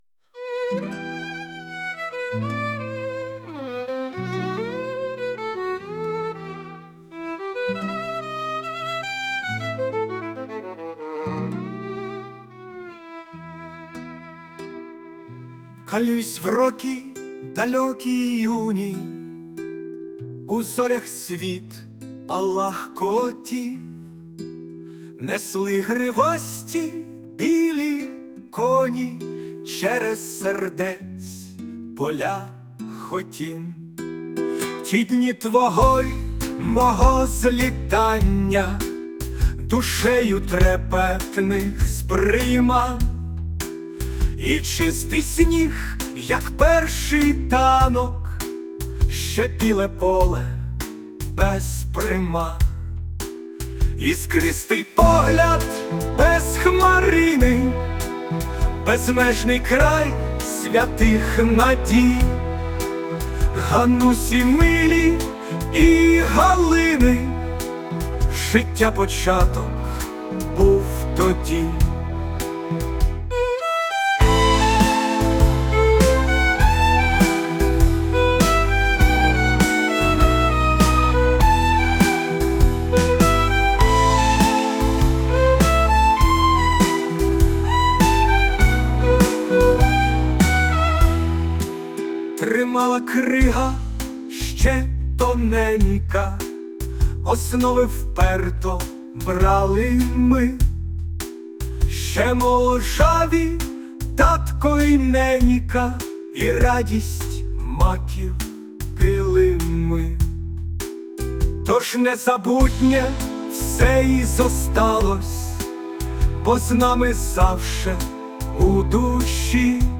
балада